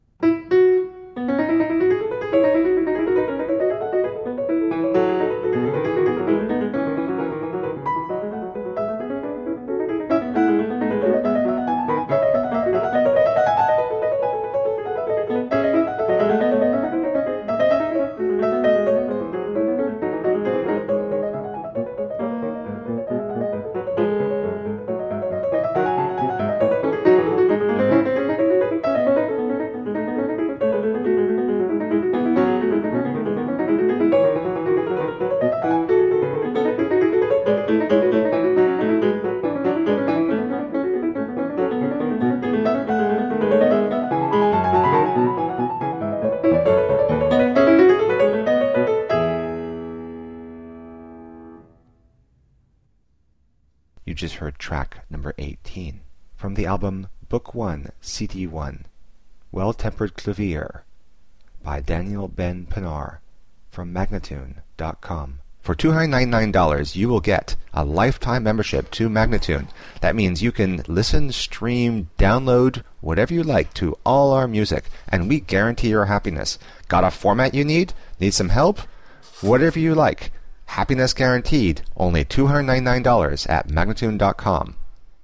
Virtuoso pianist
Classical, Baroque, Instrumental Classical, Classical Piano